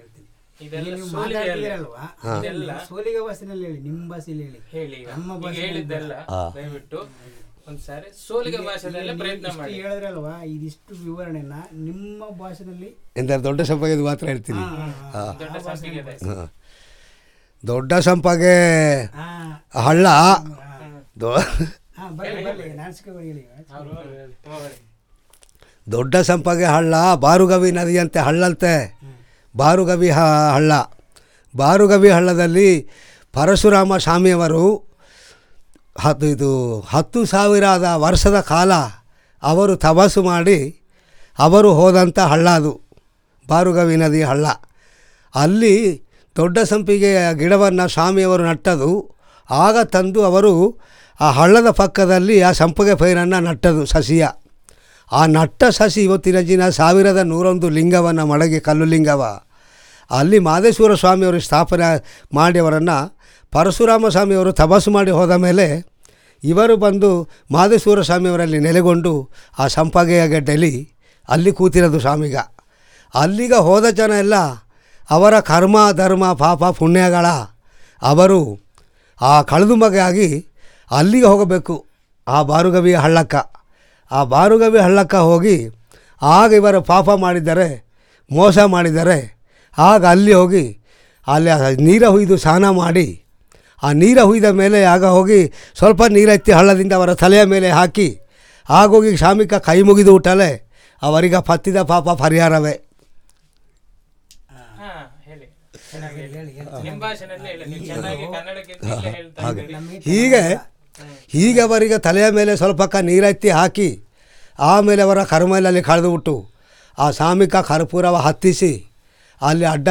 Personal Narrative on doDDasampige, a holy Champak tree